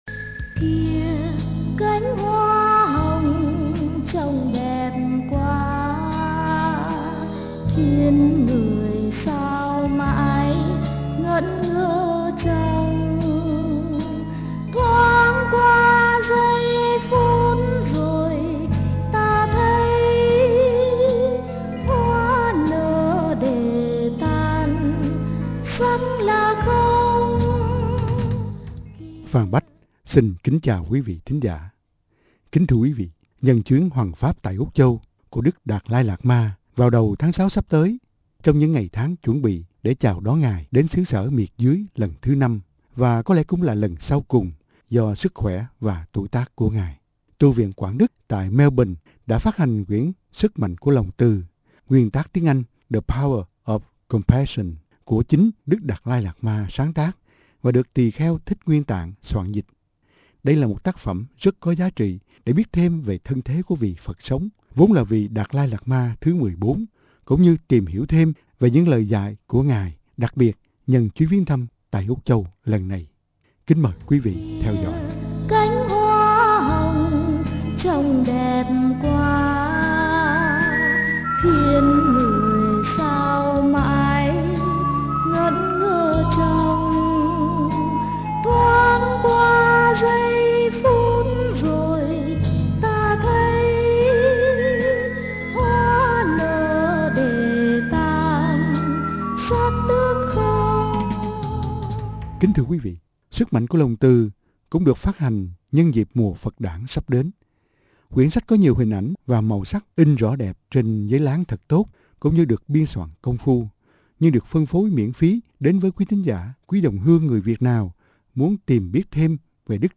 giọng đọc